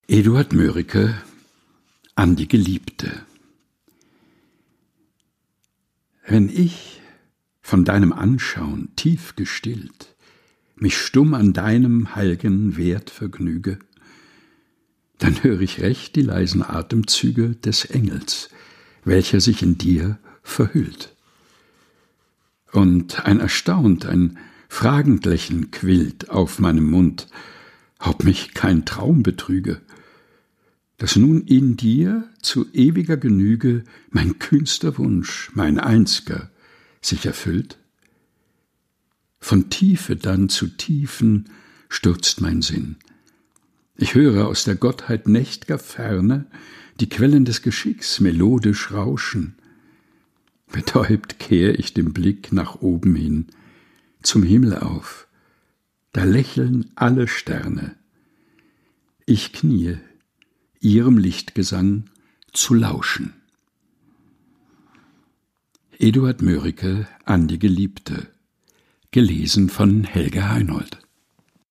Ohrenweide ist der tägliche Podcast mit Geschichten, Gebeten und Gedichten zum Mutmachen und Nachdenken - ausgesucht und im heimischen Studio vorgelesen